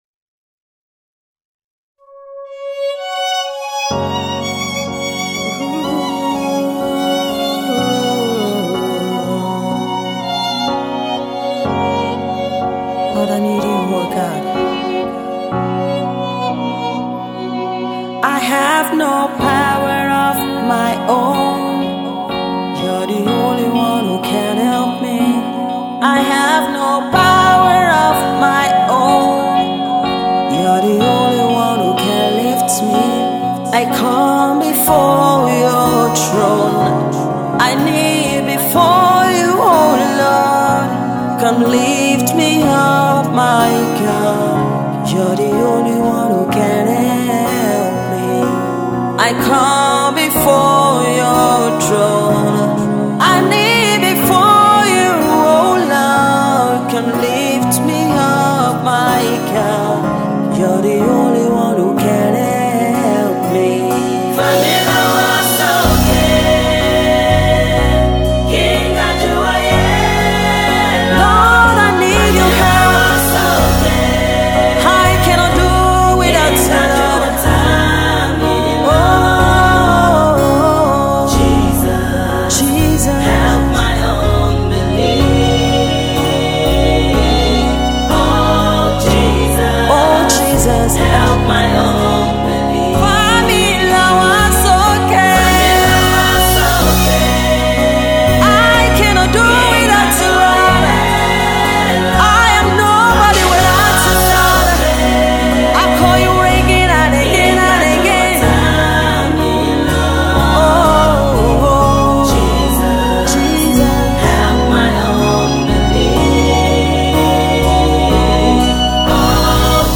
Soulful sound
With Her voice as that of an angel
gospel music